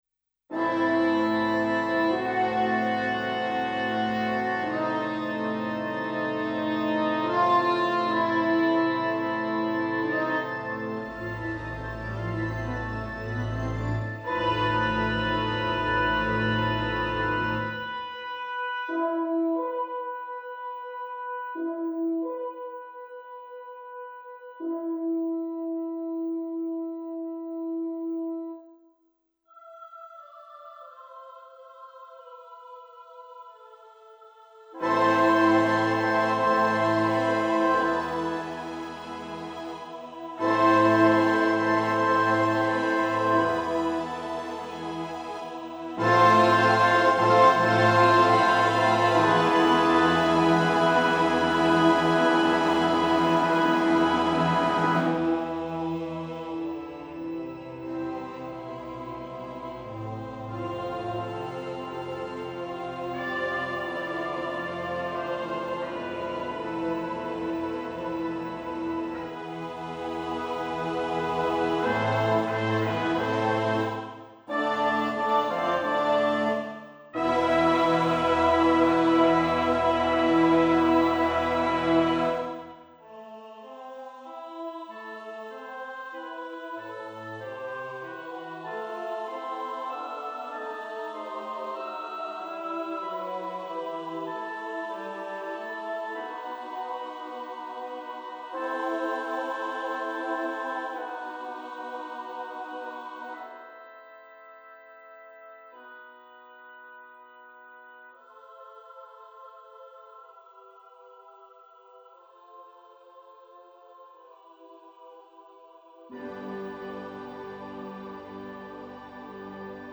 ｵﾙｶﾞﾝとｵｰｹｽﾄﾗ と合唱のための「祈り」